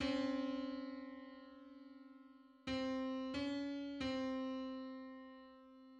Public domain Public domain false false This media depicts a musical interval outside of a specific musical context.
Sixty-ninth_harmonic_on_C.mid.mp3